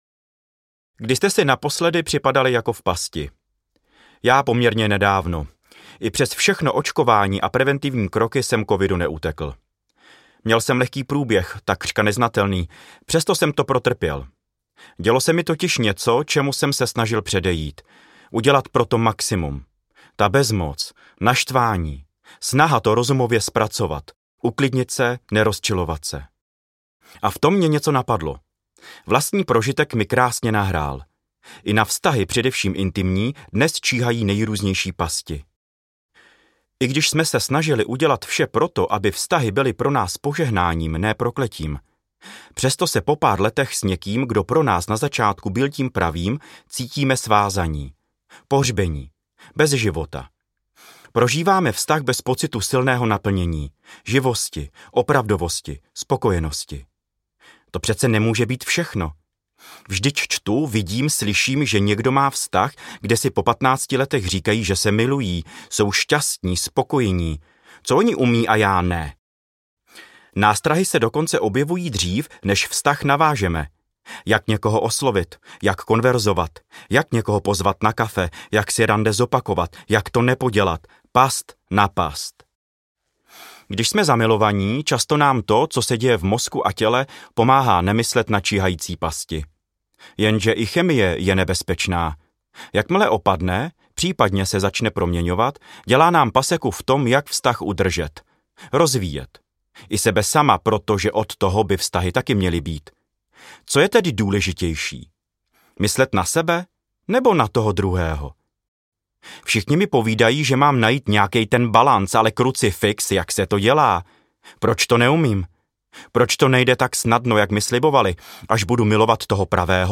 Vztahy a pasti audiokniha
Ukázka z knihy
Vyrobilo studio Soundguru